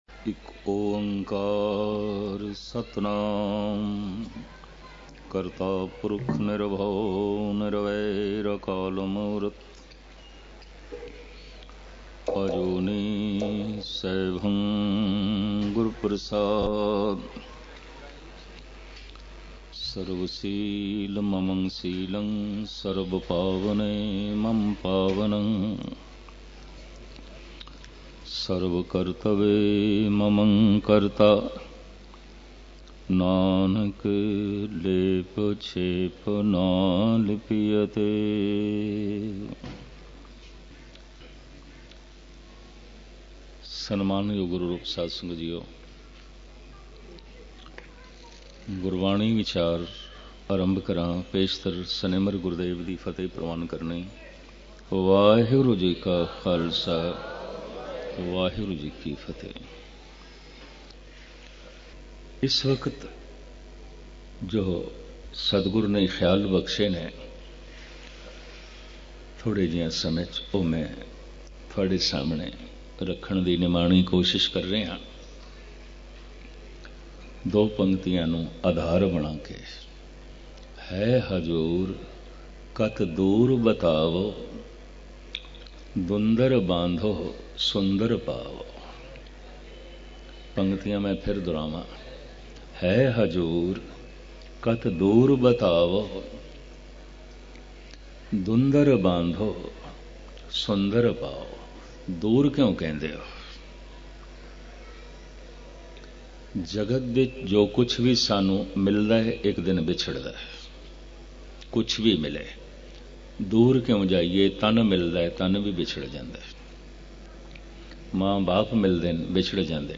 Katha